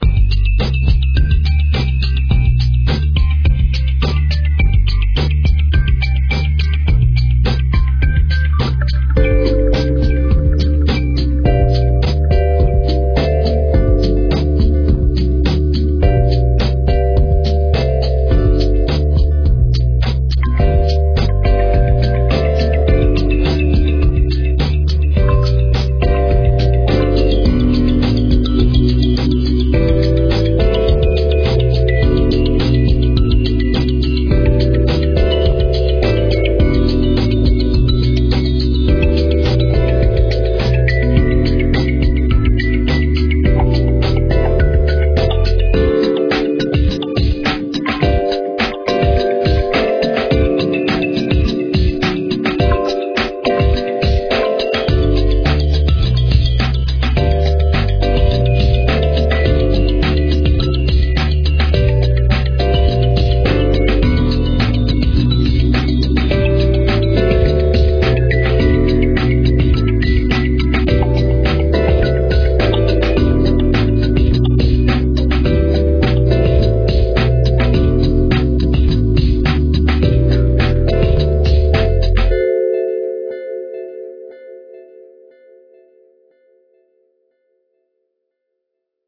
Royalty Free Music for use in any type of